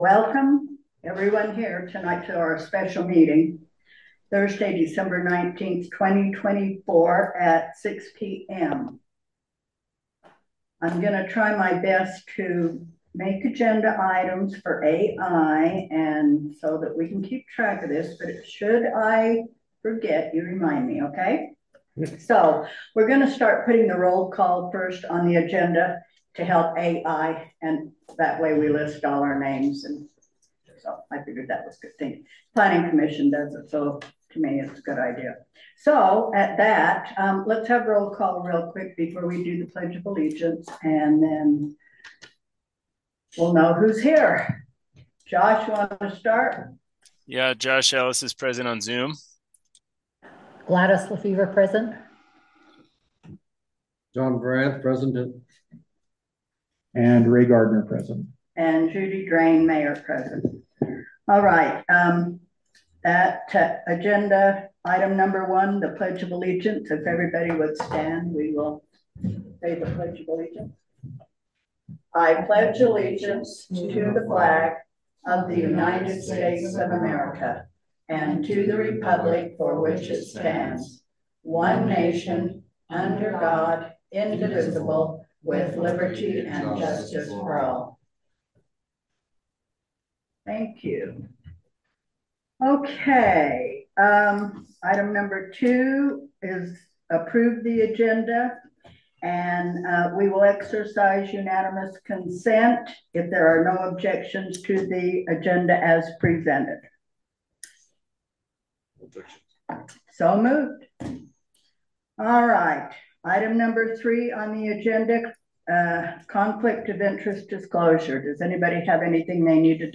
The Boulder Town Council will hold a special meeting on Thursday December 19, 2024, starting at 6:00 pm at the Boulder Community Center Meeting Room, 351 No 100 East, Boulder, UT. Zoom connection will also be available.